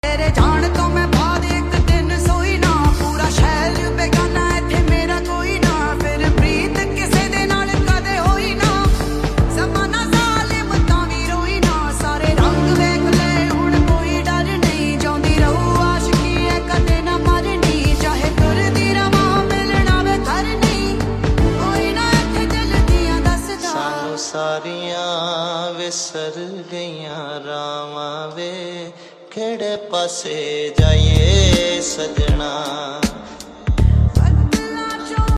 very emotional and heart-touching